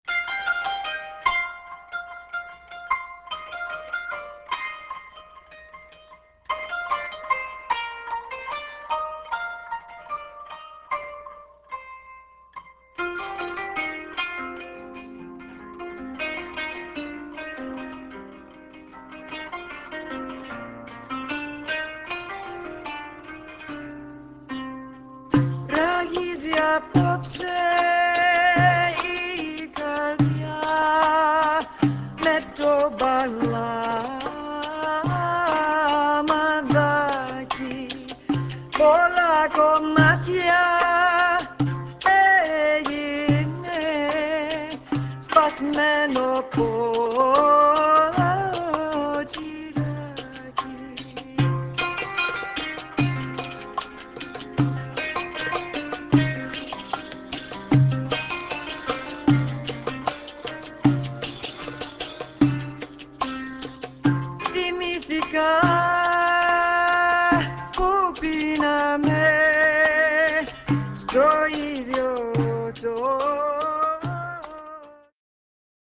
qanun (zither)
doumbec and tambourine